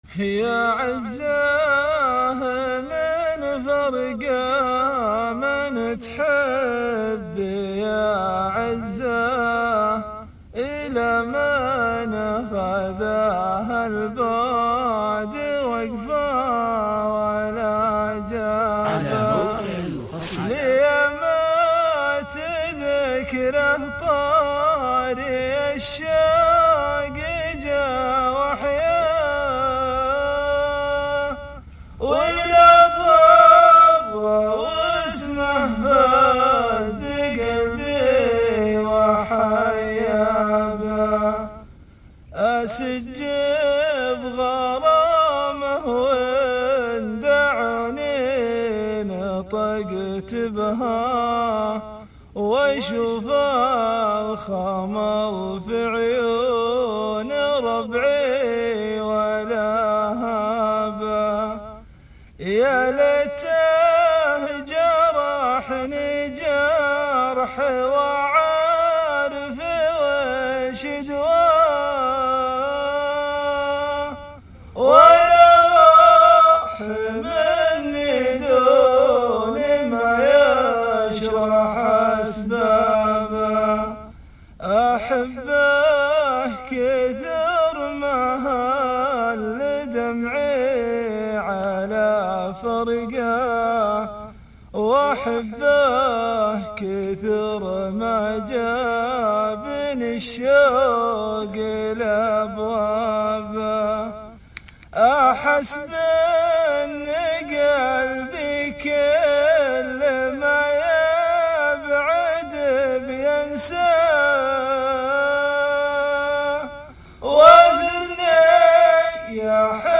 شيله   30 يناير 2012